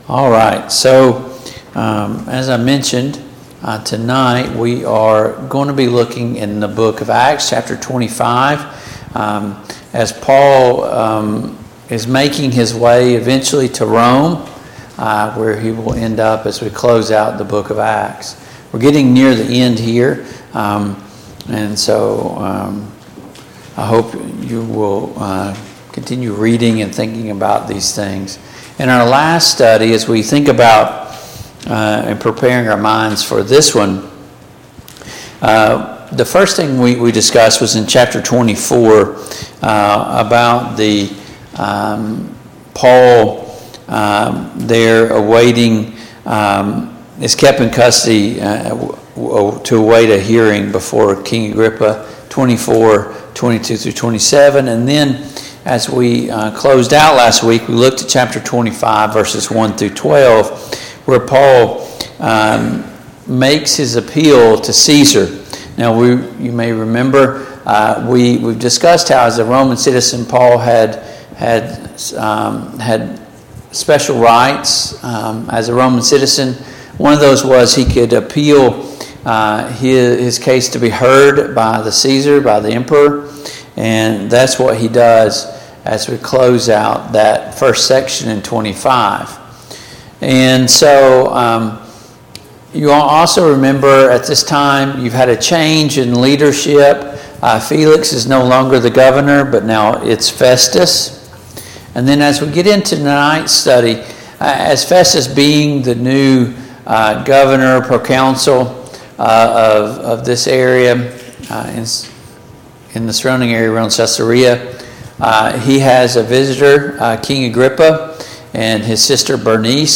Passage: Acts 25:13-27; Acts 26:1-23 Service Type: Mid-Week Bible Study